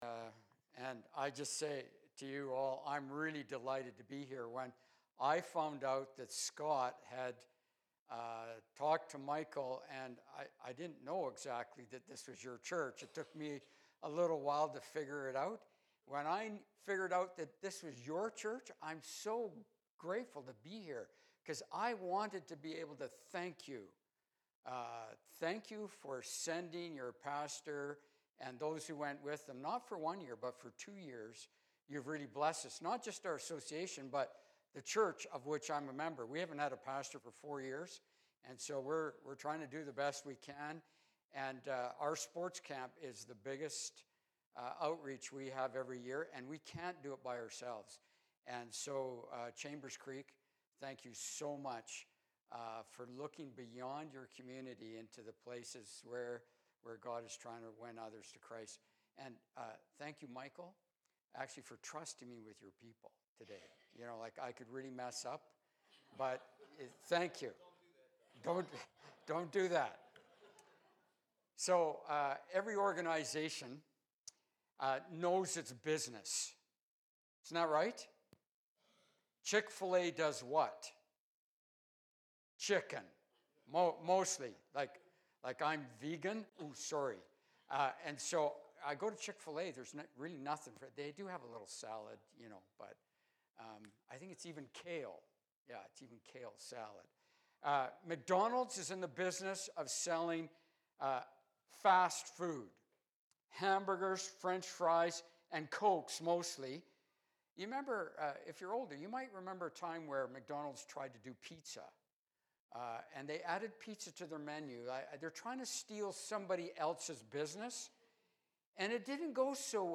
Sermons - Chambers Creek